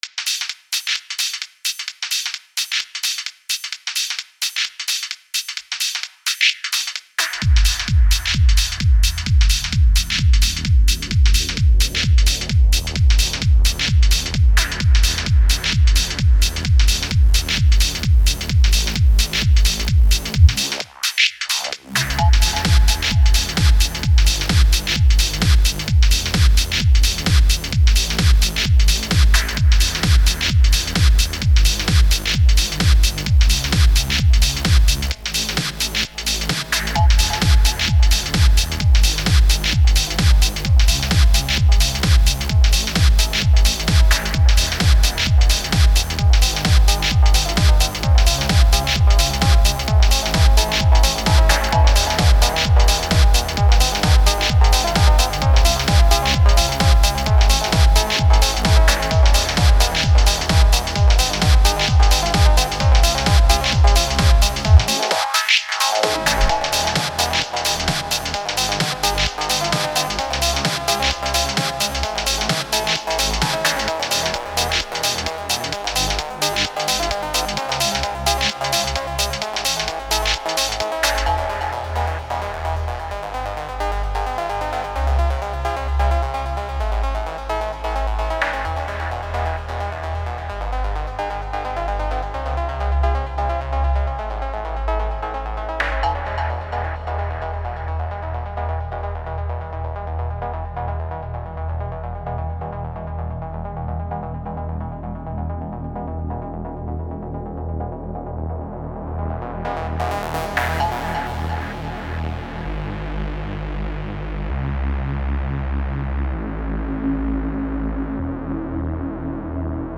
Demo trance
Давно не писал в прогрессивном жанре.